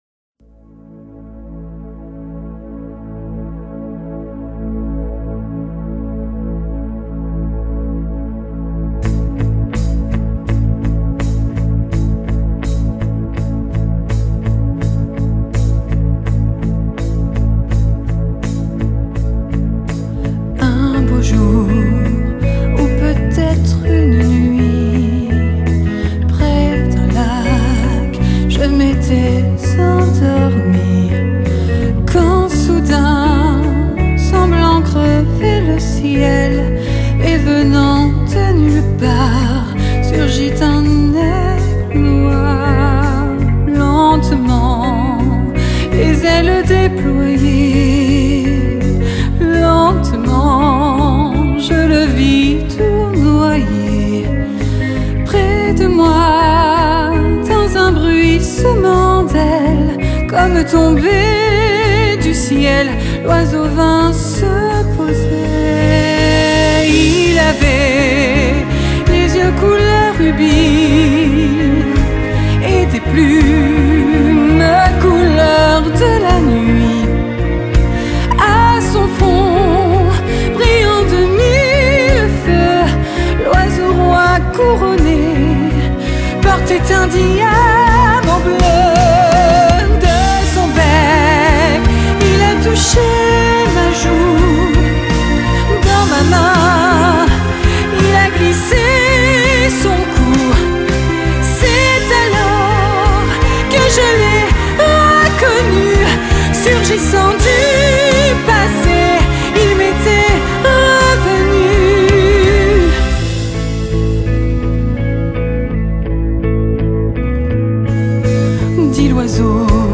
Chanteur
Contre-ténor